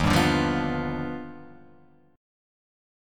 D#+M9 chord